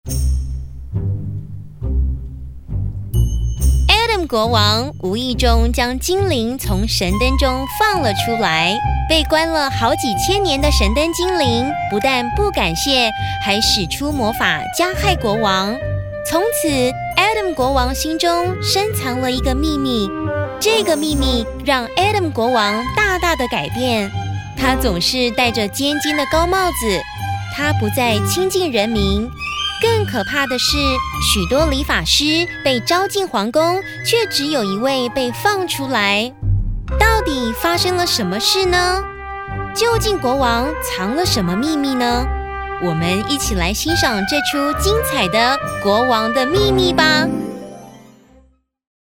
Young and energetic Mandarin Chinese and Taiwanese voice over talent.
Sprechprobe: eLearning (Muttersprache):